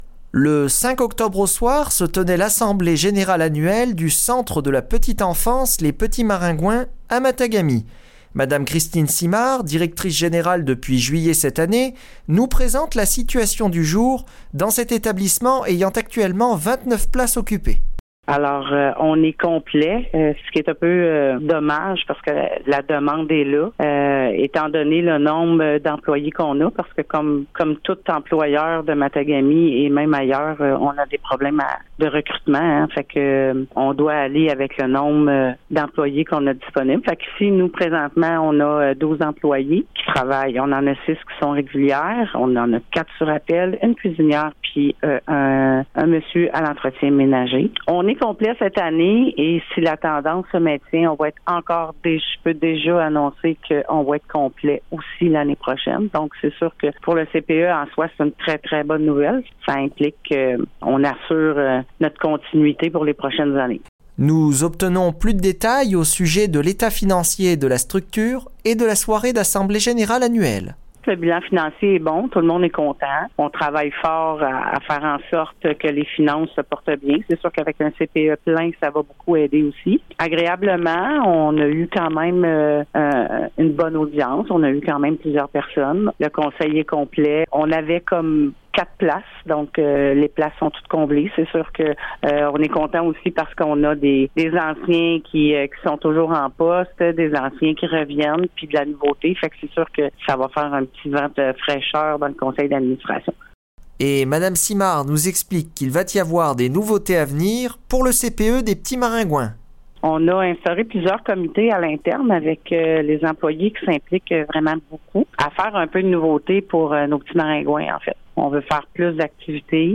Entrevue-Assemblee-generale-annuelle-CPE-Mixage-final-2.mp3